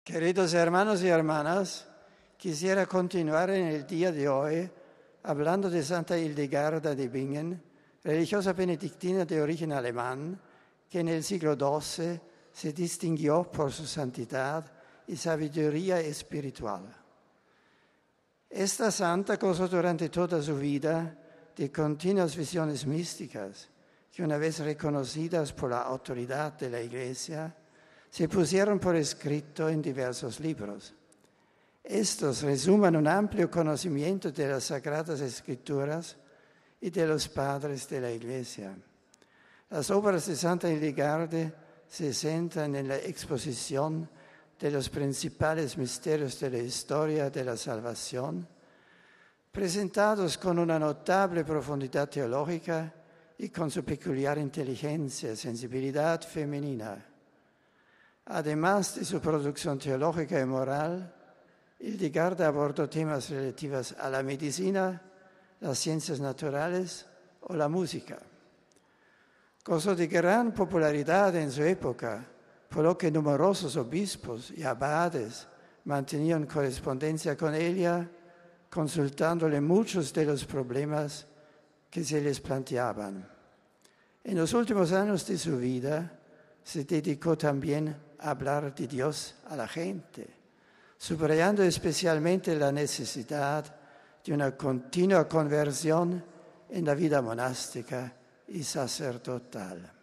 Esta fue su catequesis en nuestro idioma: